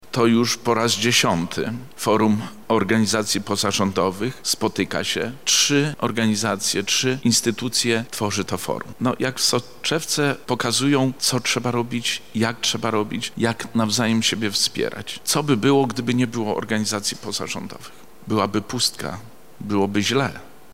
Forum w Centrum Spotkania Kultur otworzył wicemarszałek województwa lubelskiego, Zbigniew Wojciechowski: